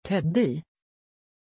Pronunciation of Teddie
pronunciation-teddie-sv.mp3